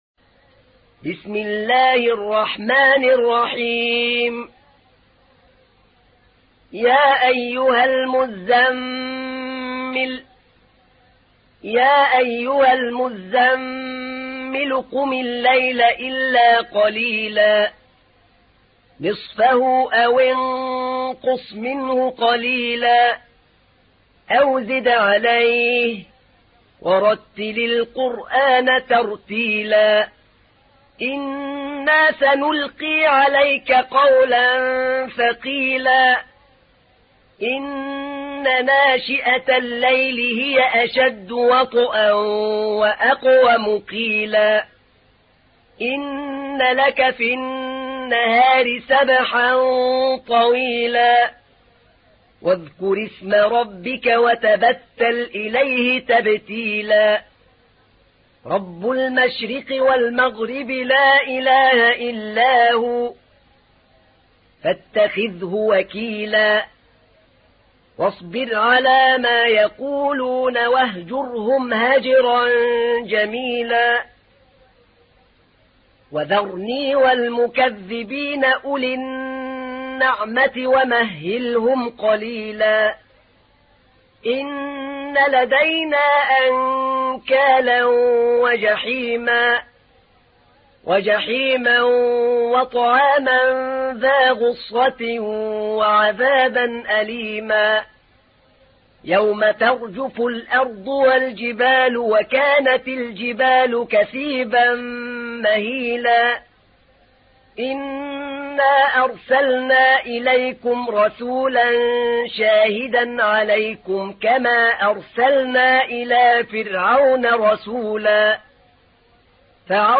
تحميل : 73. سورة المزمل / القارئ أحمد نعينع / القرآن الكريم / موقع يا حسين